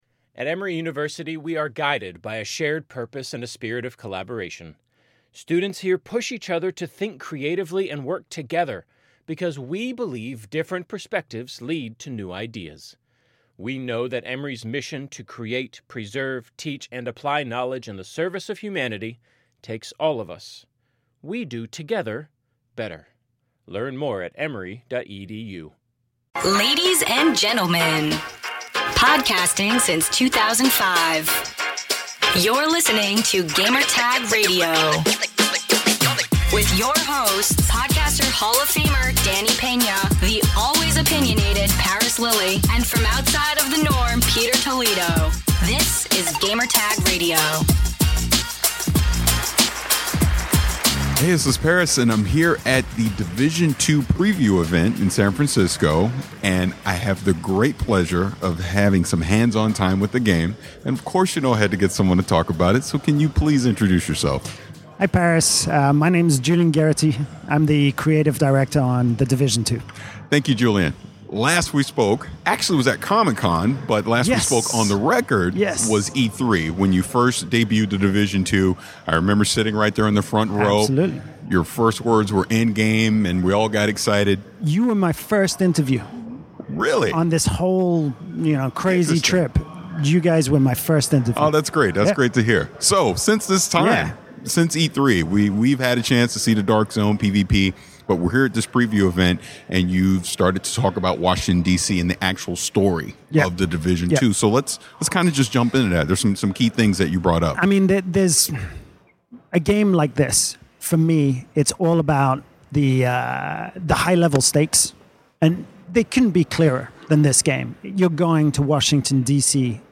The Division 2 Interview